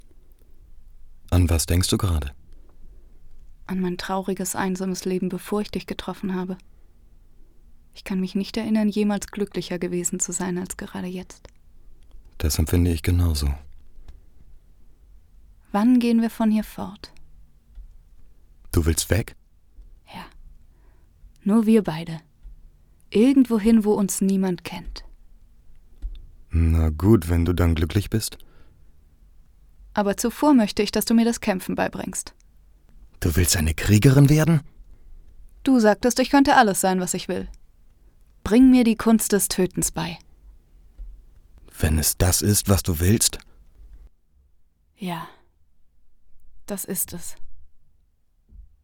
lebendig, kräftig, ruhig, variabel, Trickfilm, Comic, jung, dynamisch, frech
schwäbisch
Sprechprobe: Sonstiges (Muttersprache):